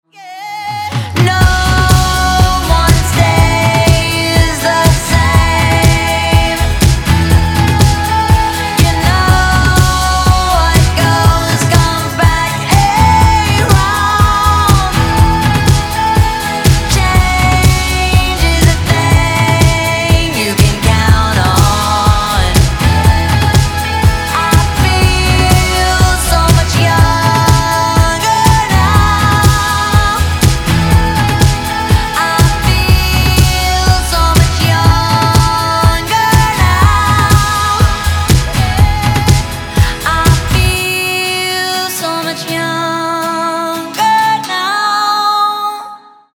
• Качество: 320, Stereo
поп
гитара
громкие
женский вокал
dance